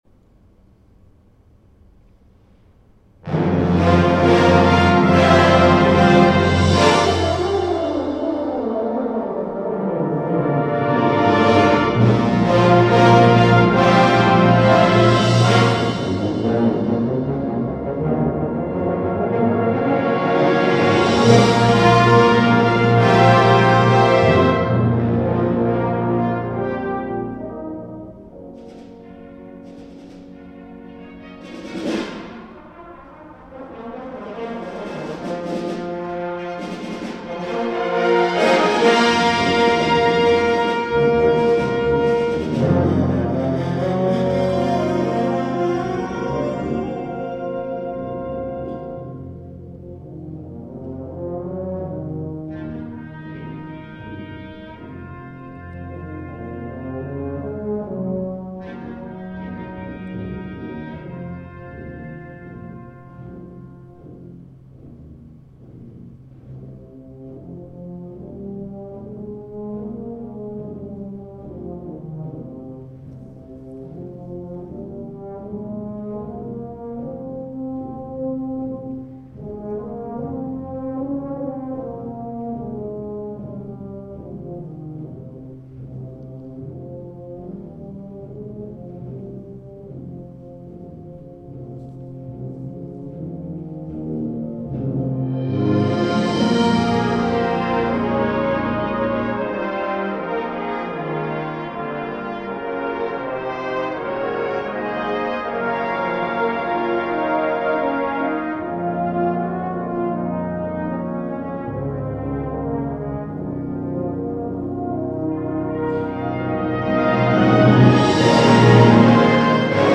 Dalkeith & Monktonhall Brass Band playing Trittico - James Curnow, 1st Section National Finals, Cheltenham 2022